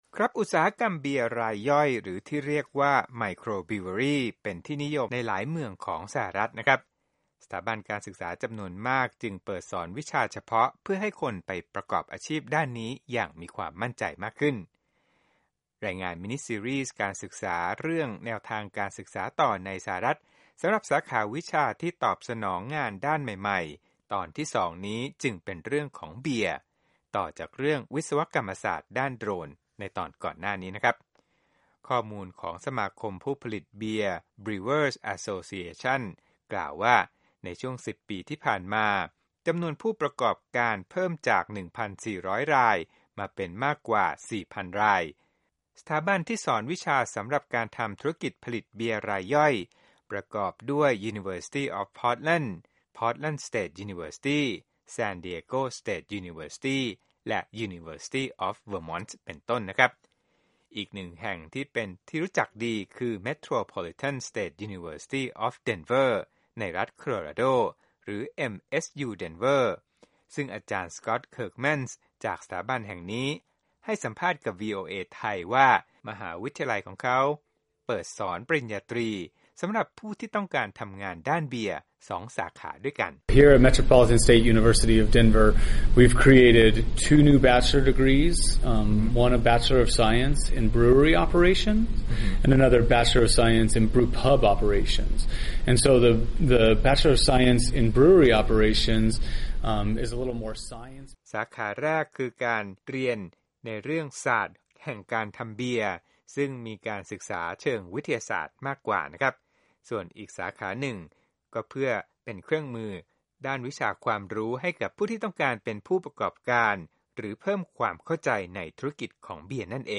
รายงานมินิซีรีส์การศึกษาเรื่องแนวทางการศึกษาต่อในสหรัฐฯ สำหรับสาขาวิชาที่ตอบสนองงานด้านใหม่ๆ ตอนที่สองเป็นเรื่องของ “เบียร์” ต่อจากเรื่องวิศวกรรมศาสตร์ด้าน “โดรน” ในตอนก่อนหน้านี้